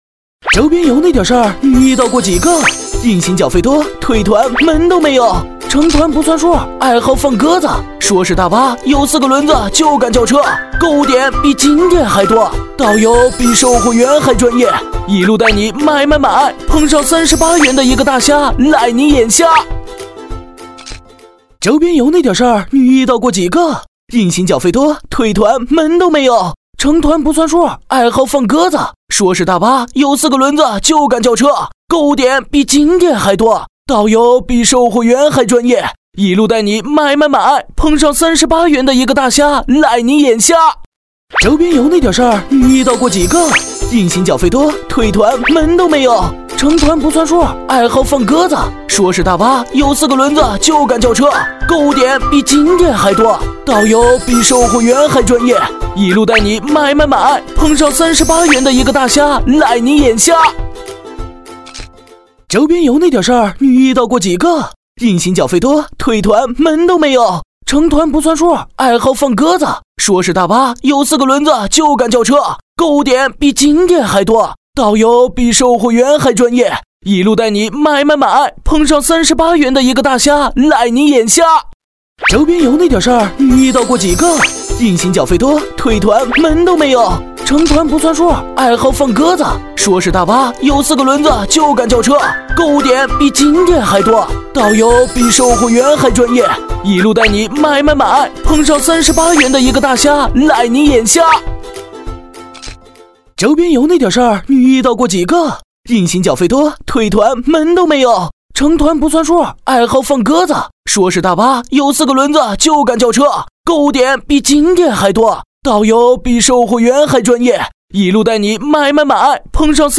国语青年积极向上 、时尚活力 、素人 、脱口秀 、男飞碟说/MG 、100元/分钟男B073 国语 男声 病毒 飞碟说 MG动画-买买飞碟说-欢快活泼 积极向上|时尚活力|素人|脱口秀